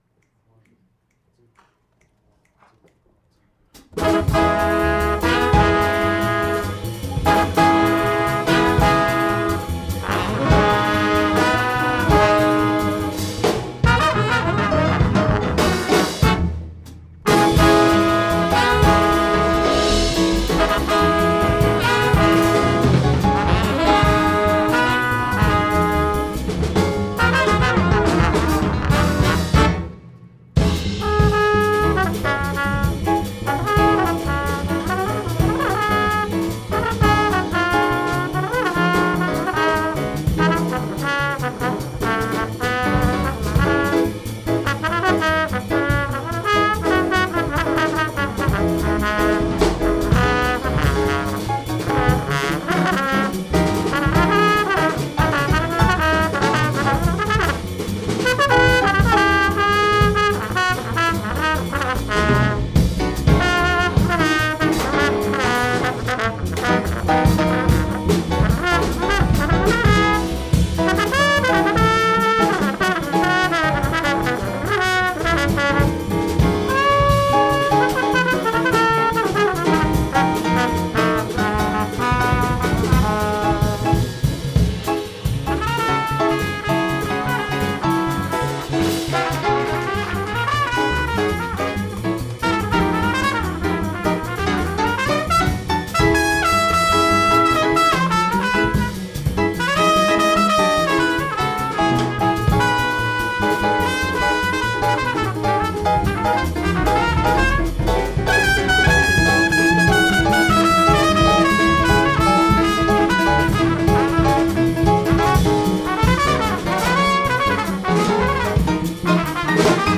Drums & Congas
Piano
Trumpet & Flugelhorn
Trombone & Vocals
Tenor Sax & Clarinet
Bass